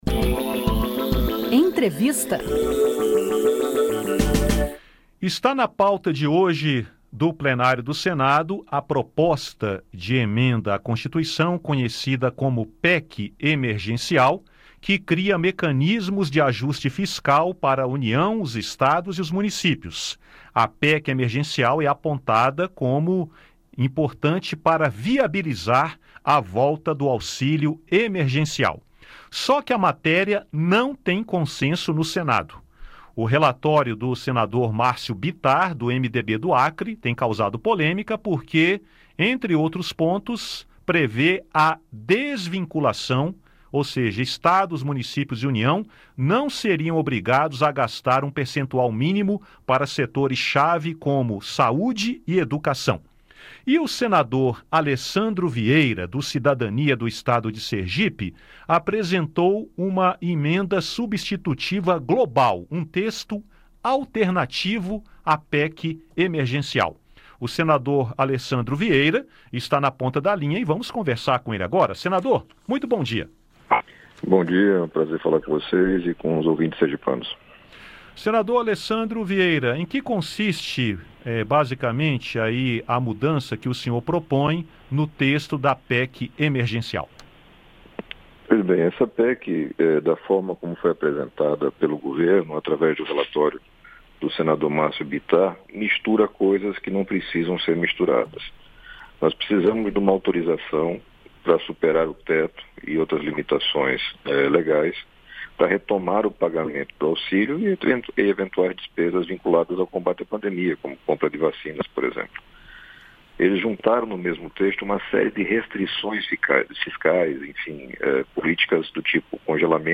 Para o senador Alessandro Vieira (Cidadania-SE), não é verdade que o pagamento do auxílio dependa da aprovação da PEC. O auxílio é urgente para que as pessoas carentes não continuem passando fome, disse o  senador à Rádio Senado. Ouça a entrevista.